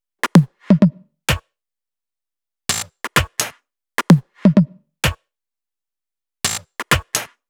Index of /VEE/VEE Electro Loops 128 BPM
VEE Electro Loop 152.wav